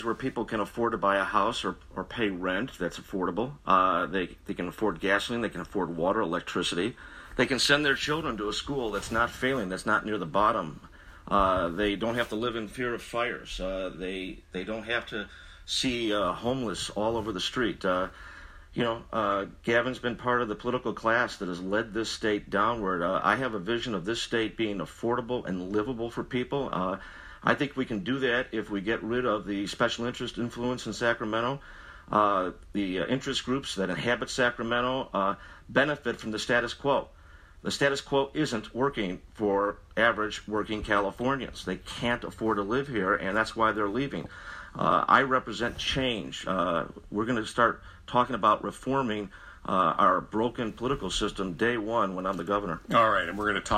加州州长候选人民主党人纽森（Gavin Newsom）和共和党人考克斯（John Cox）8日上午10点在旧金山公共广播电台KQED展开辩论，解释为什么选民应该选他。
共和党人考克斯对加州未来的期许：